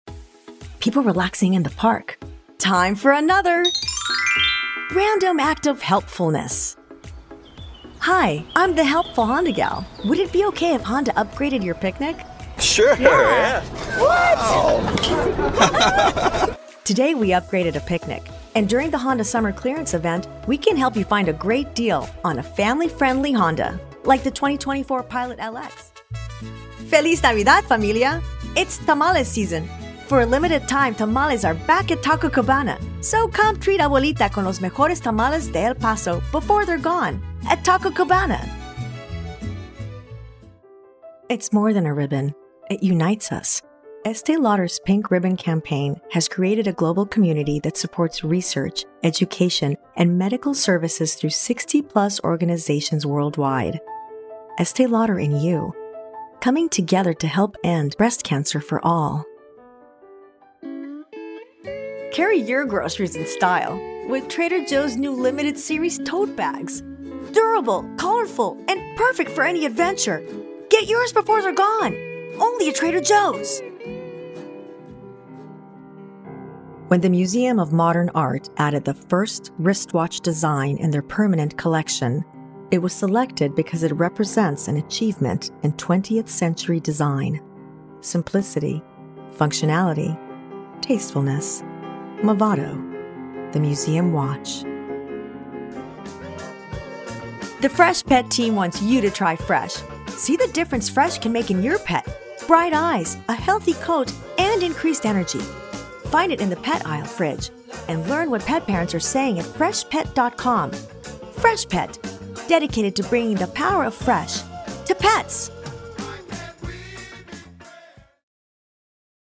Voiceover
Commercial Demo
Home Studio:
Mic: AKG C1000s, Fethead Preamp, ROKITPOP Pop Filter